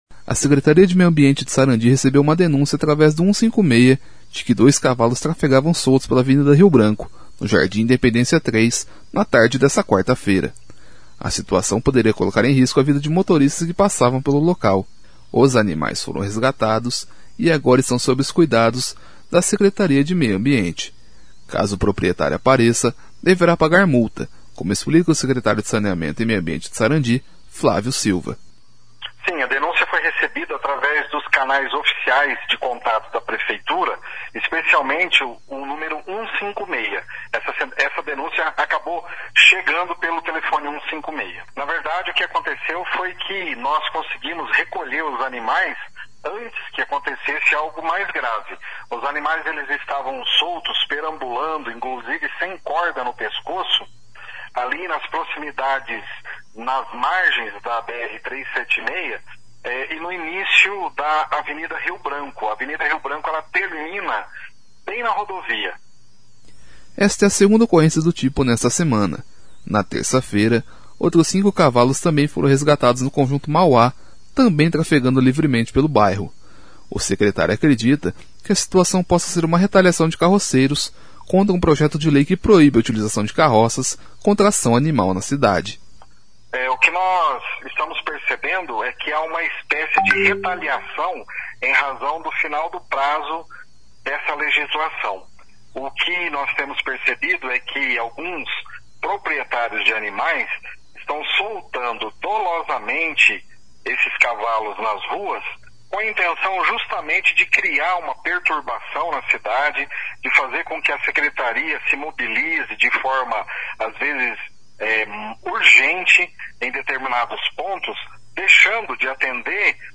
Caso o proprietário apareça, deverá pagar multa, como explica o Secretário de Saneamento e Meio Ambiente de Sarandi, Flávio Silva.